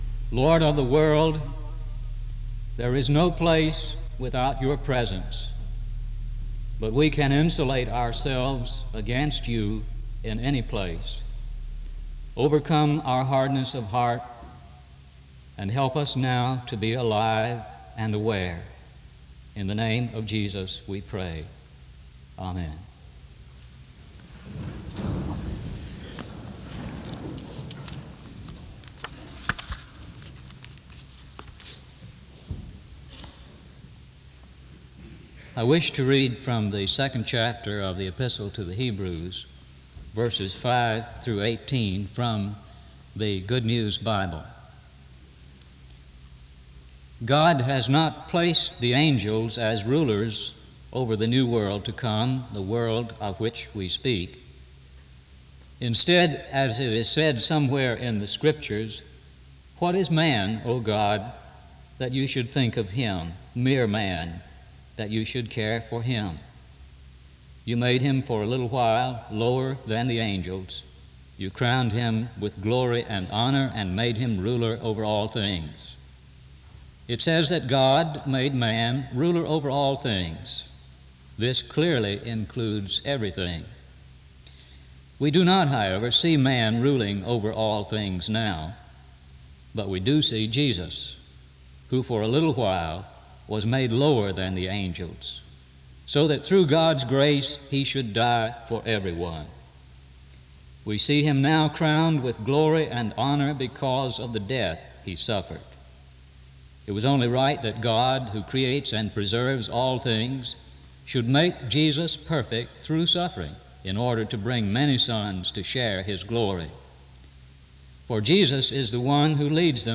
The service starts with a prayer from 0:00-0:22. Hebrews 2:5-18 is read from 0:35-3:30. A prayer is offered from 3:31-4:05.